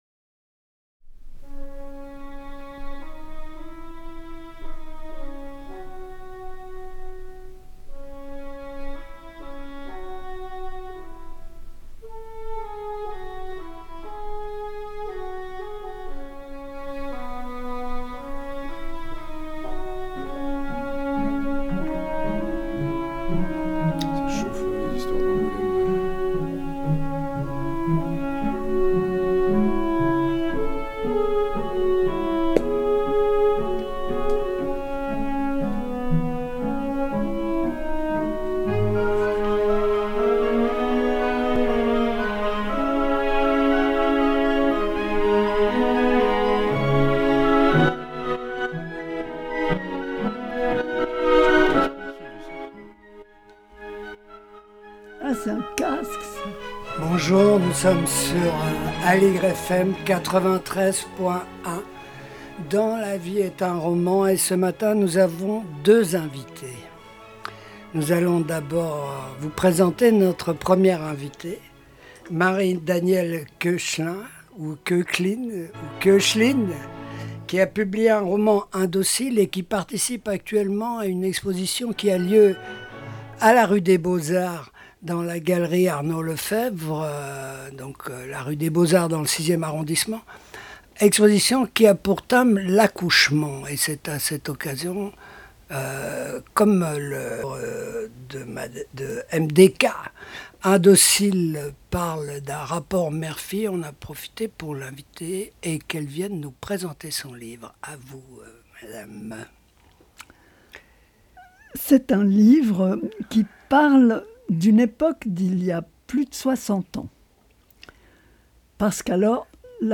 Interview de J.-C. Menu pour son album 30 – 40, paru chez l'Apocalypse